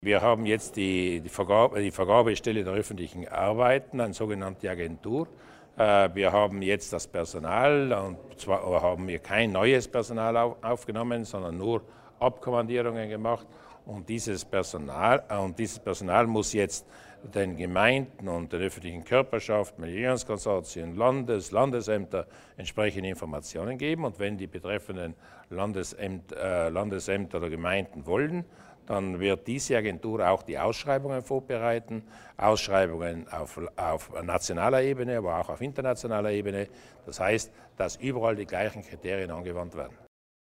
Landeshauptmann Durnwalder erläutert die Aufgaben der Vergabeagentur für öffentliche Aufträge